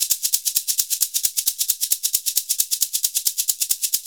Index of /90_sSampleCDs/Univers Sons - Basicussions/11-SHAKER133